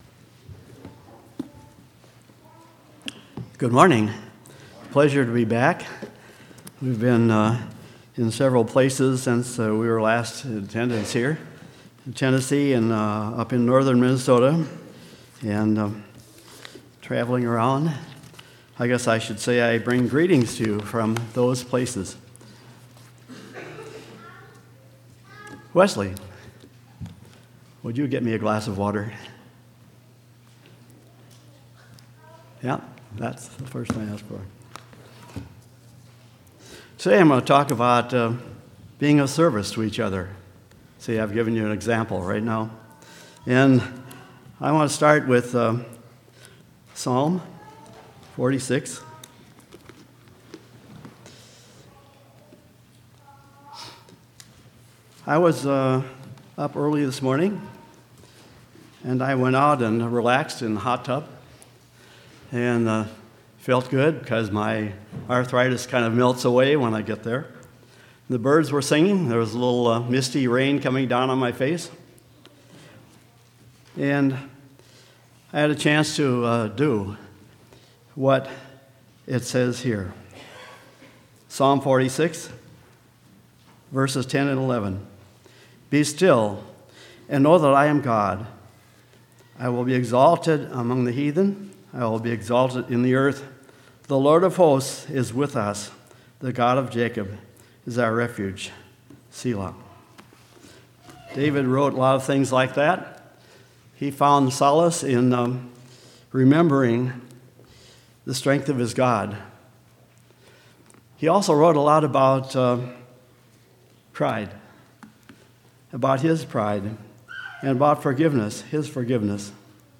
5/31/2015 Location: Temple Lot Local Event